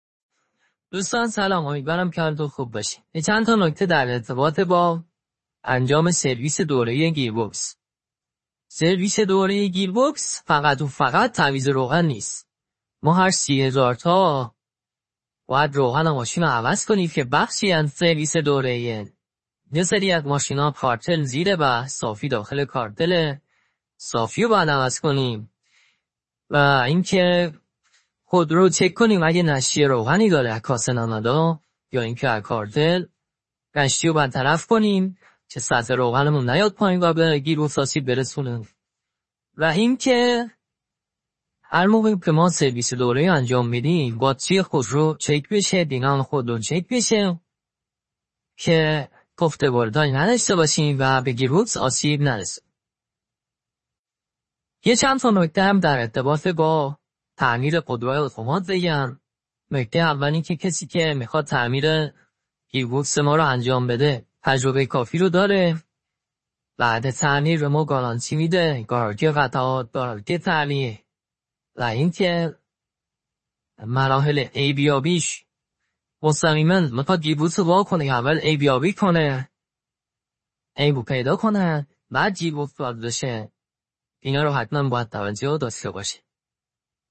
New-Project-Male-voice.mp3